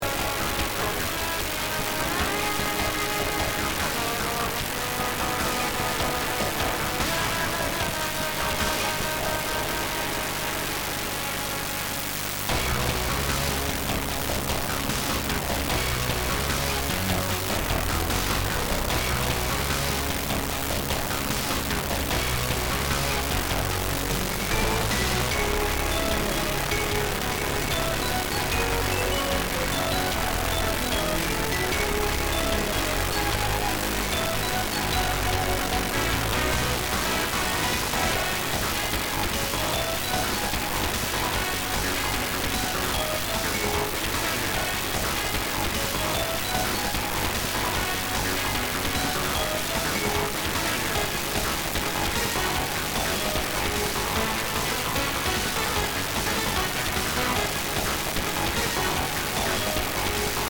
No idea why it sounds messy.